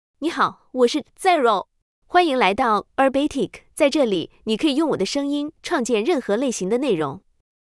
Xiaorou — Female Chinese (Mandarin, Simplified) AI Voice | TTS, Voice Cloning & Video | Verbatik AI
Xiaorou is a female AI voice for Chinese (Mandarin, Simplified).
Voice sample
Listen to Xiaorou's female Chinese voice.
Xiaorou delivers clear pronunciation with authentic Mandarin, Simplified Chinese intonation, making your content sound professionally produced.